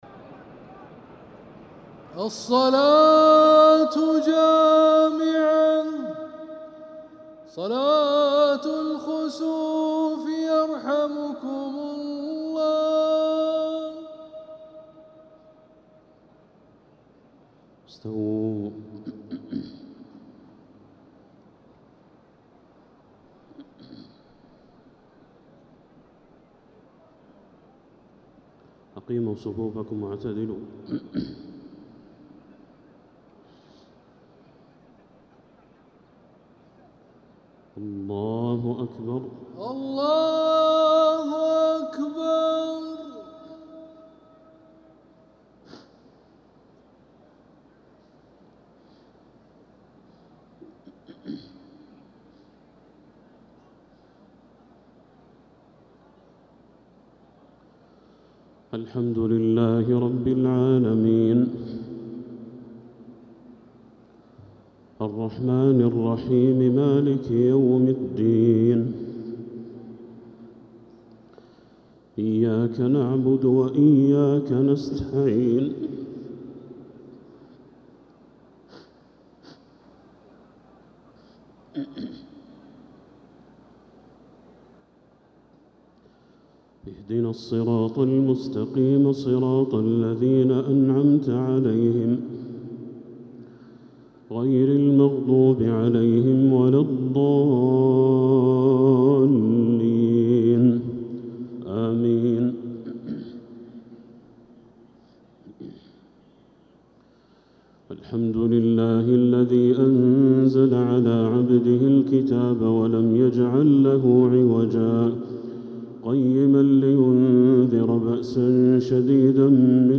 صلاة الخسوف 15 ربيع الأول 1447هـ.